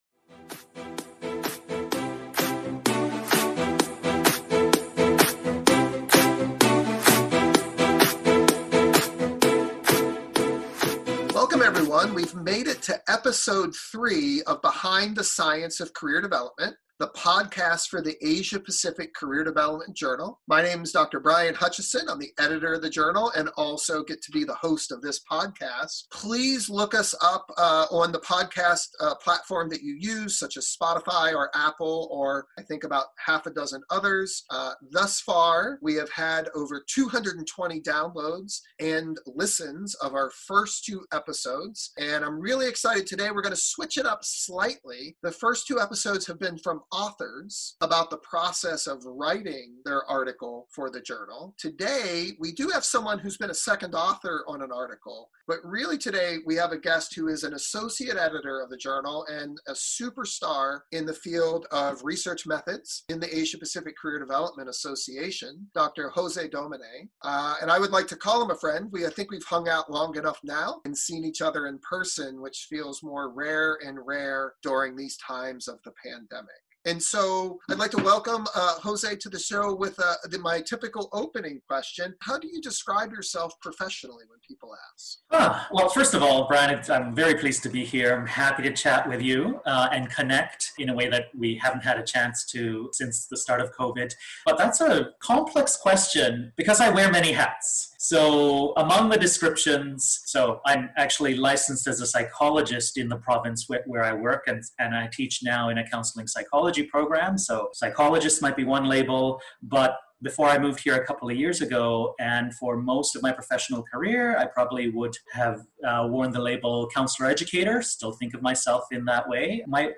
This wide-ranging conversation covered several topics of interest for career practitioners and scholars including his background, how an academic journal works, how to think about research, and the process of developing scholarship for publication. This conversation really digs into the basics of professional scholarship including: Key terms in the academic scholarship process.